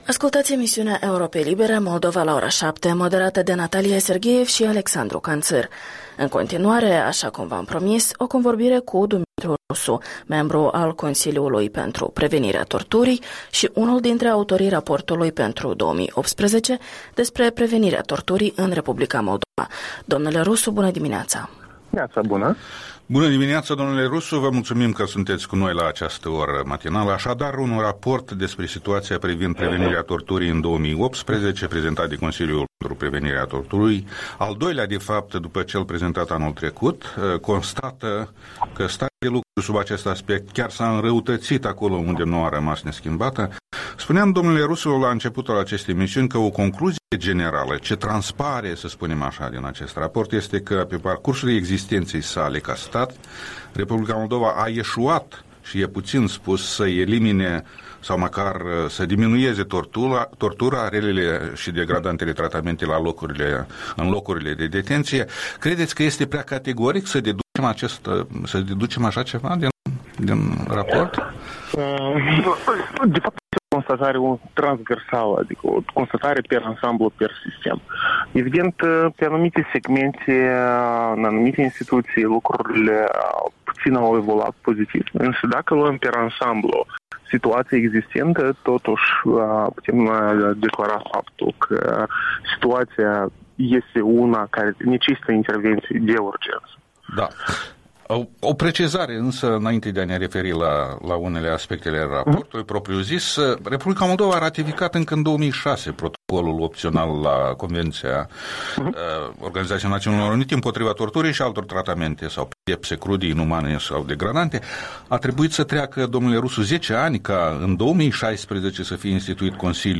Interviul dimineții cu un membru al Consiliului pentru Prevenirea Torturii (CpPT).